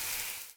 acid-end.ogg